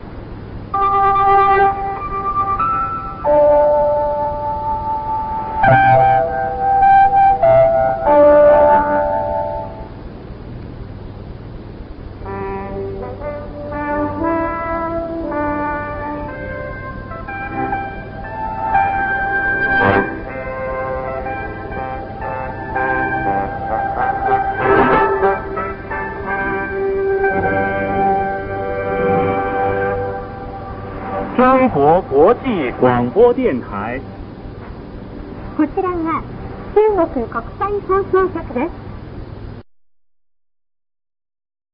放送開始部分の録音が聴けます